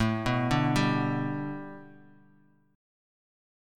A7sus2sus4 chord